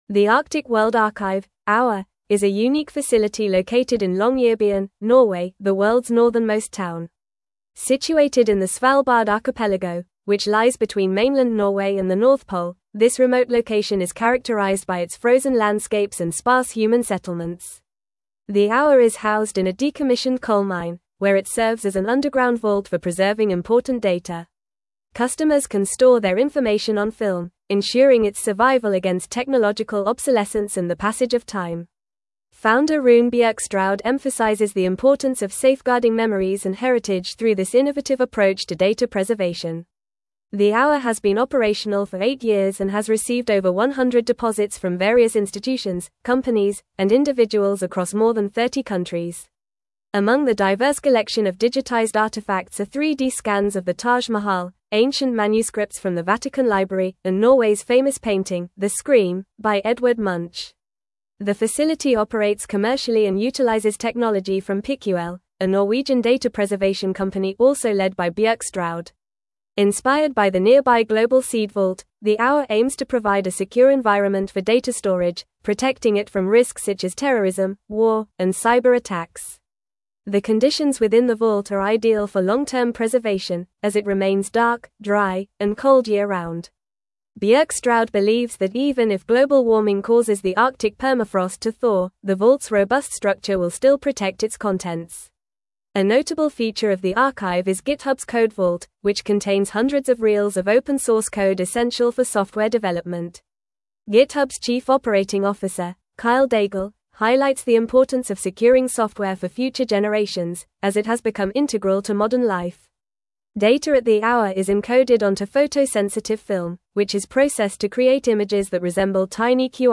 Fast
English-Newsroom-Advanced-FAST-Reading-Preserving-the-Future-at-The-Arctic-World-Archive.mp3